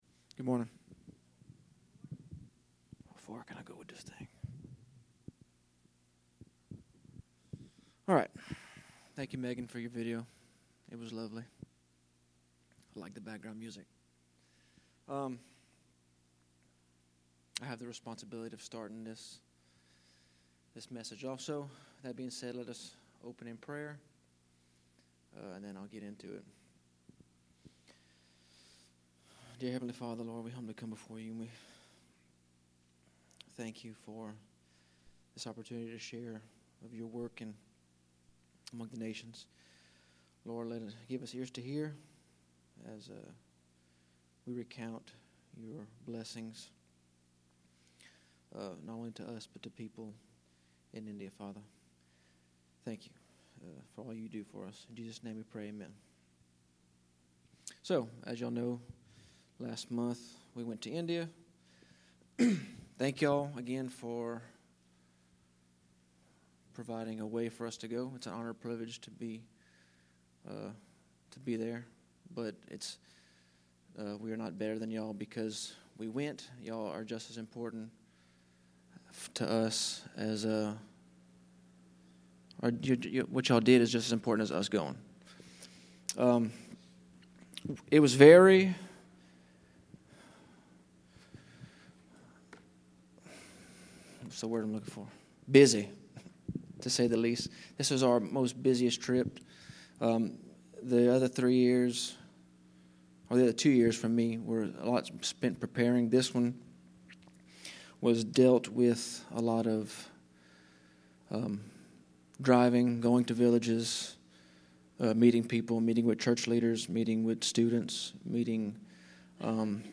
A message from the series "Be The Church." We are called to share the Gospel with the nations.